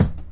Kick 5.wav